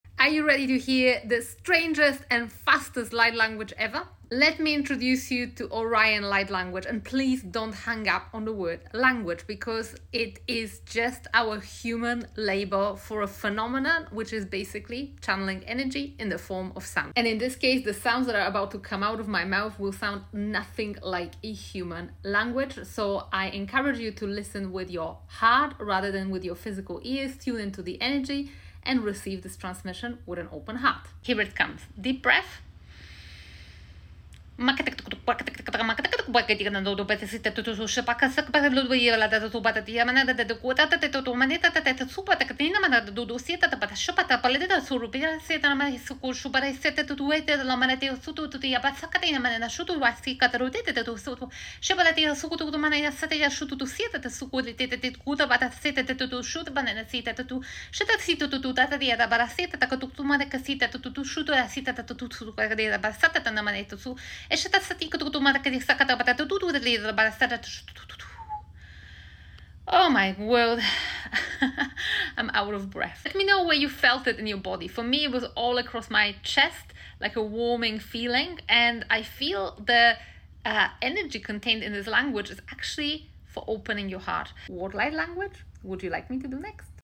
It sounds nothing like any human language - so please receive this transmission with an open heart. Light Language is simply channelling energy in the form of sound - and “language” is just an unfortunate term to describe this phenomenon.